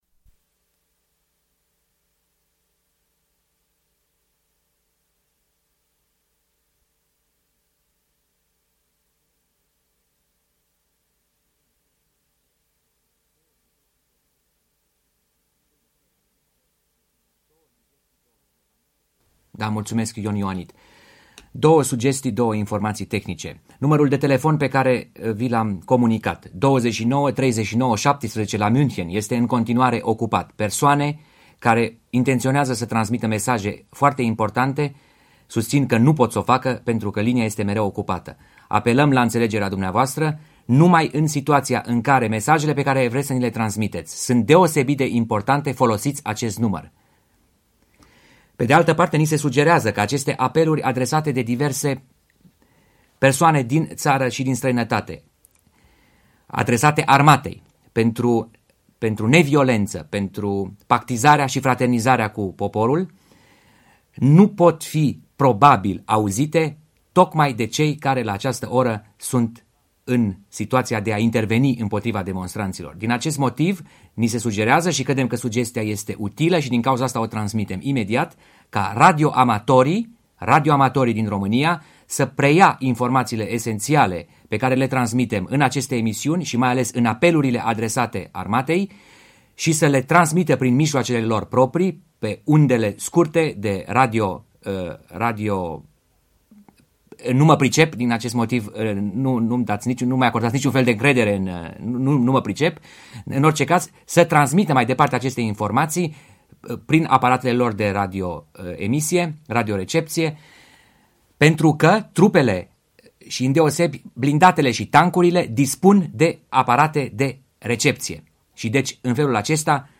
22.12.1989 -studioul special al „Actualității românești” : apel George Palade și Nina Cassian